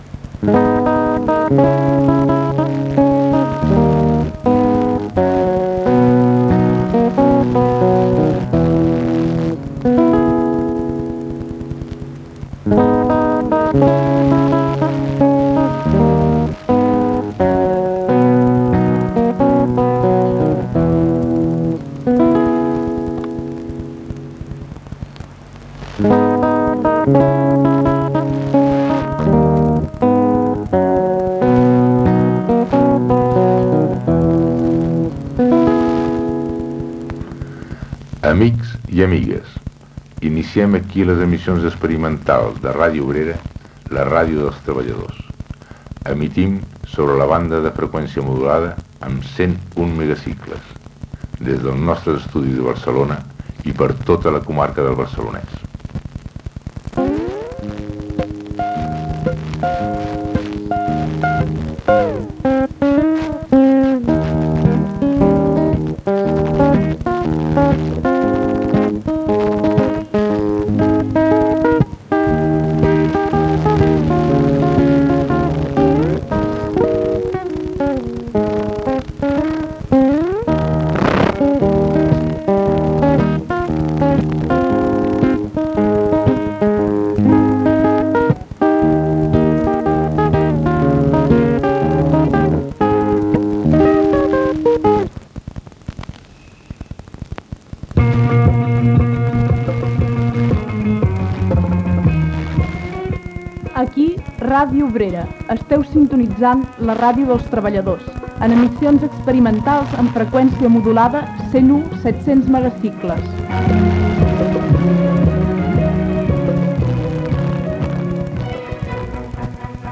5a652a919d178257ef5b63fc3bcd4507a837fba1.mp3 Títol Ràdio Obrera Emissora Ràdio Obrera Titularitat Tercer sector Tercer sector Política o sindical Descripció Sintonia i inici d'emissió amb la programació del dia.
Banda FM